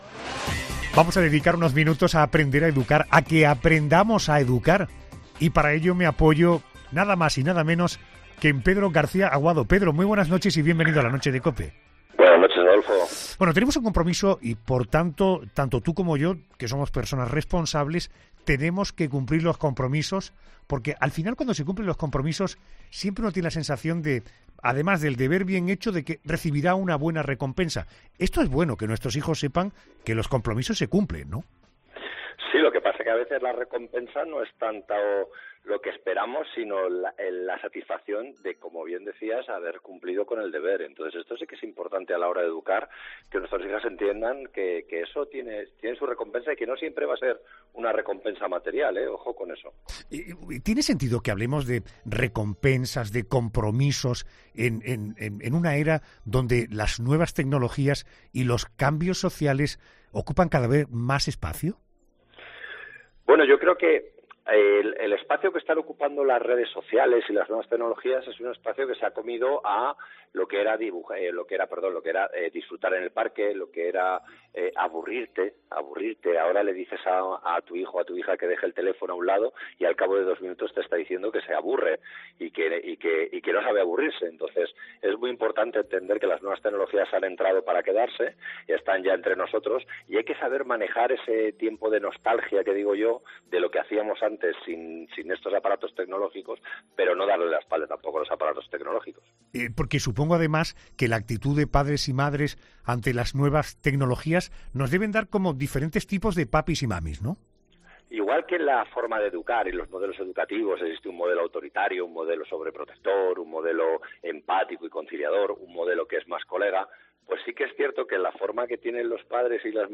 El intervencionista familiar de ‘La Noche de COPE’, Pedro García Aguado, sigue enseñándonos cómo aprender a educar a nuestros hijos